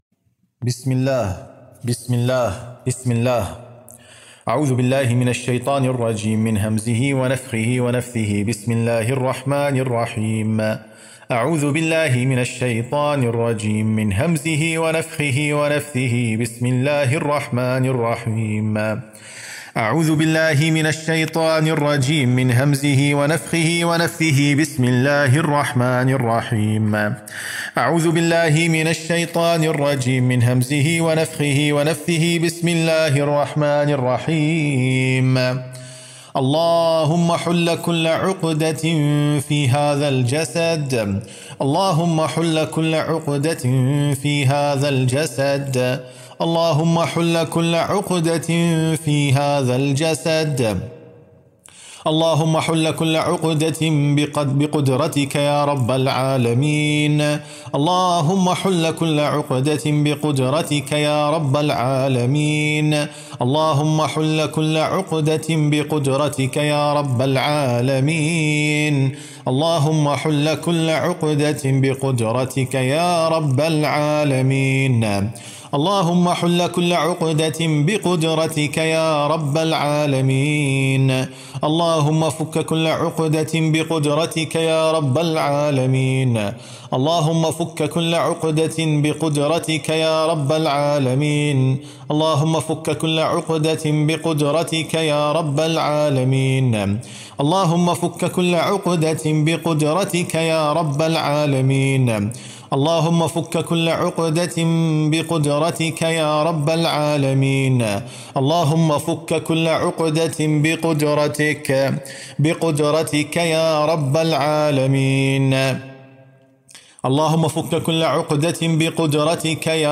যাদুর গিঁট নষ্টের রুকইয়াহ
গিঁট-নষ্টের-রুকইয়াহ-—-RUQYAH-FOR-OPENING-THE-KNOTS.mp3